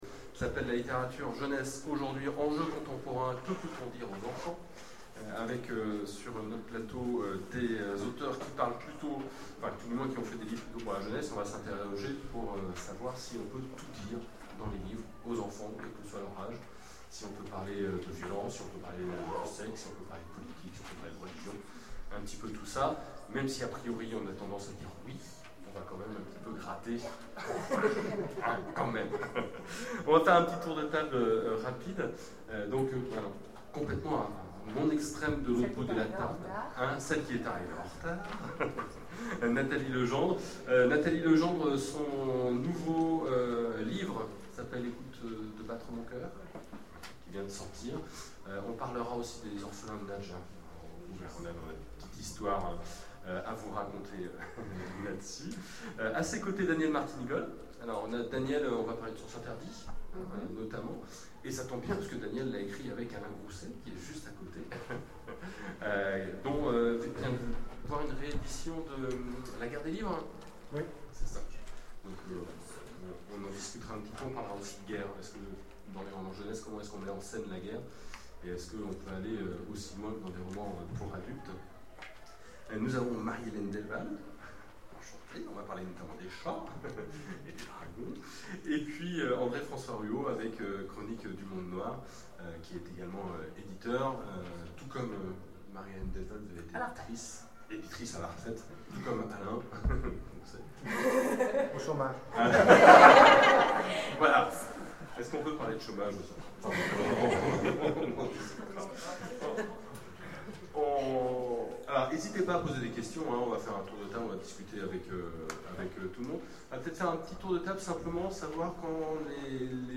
Oniriques 2013 : Conférence La littérature jeunesse aujourd’hui, enjeux contemporains